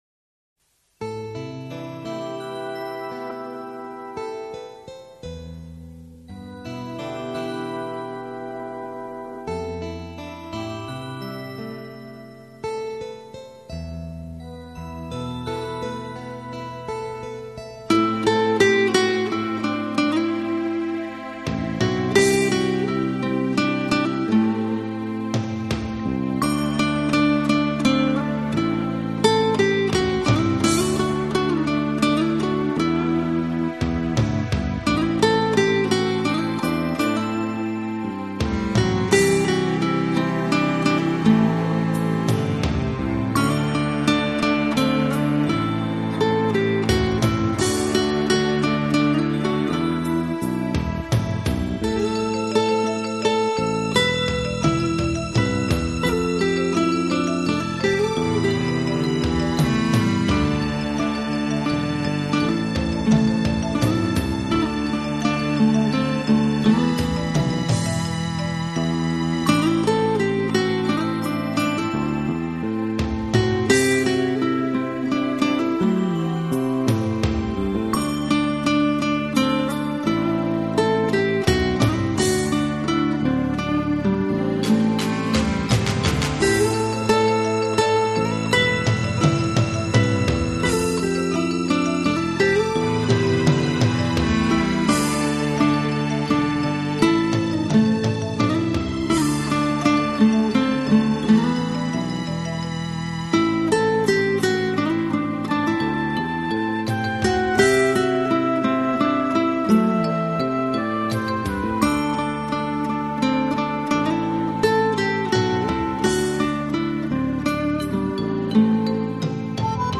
首张发烧级吉它东方情全力奉献，乐曲首首动听耳熟，演奏风格时尚新颖。